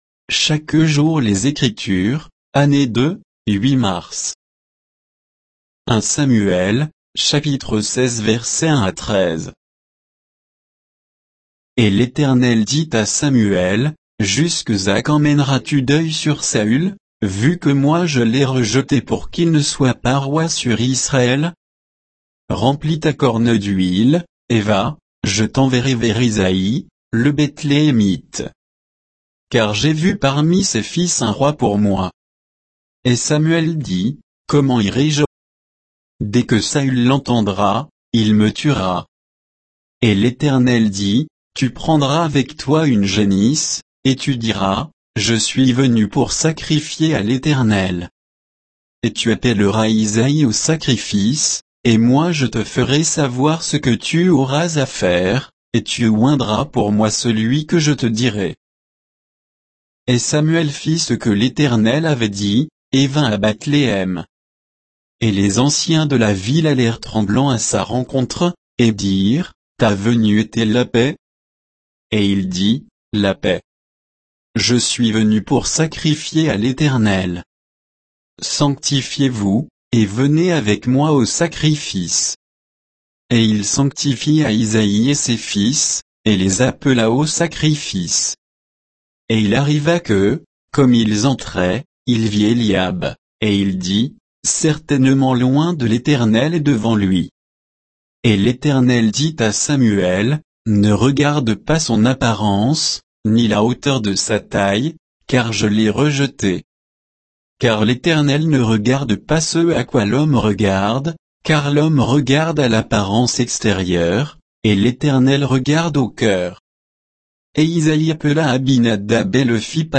Méditation quoditienne de Chaque jour les Écritures sur 1 Samuel 16, 1 à 13